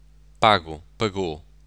9.3.2.c: portugisisk [ˈpagʊ pɐˈgo]